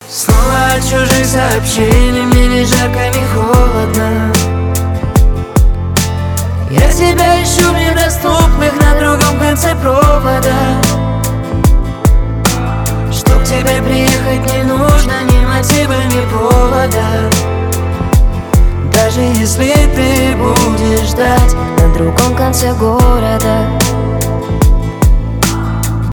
романтические
поп , дуэт
пианино
красивый женский голос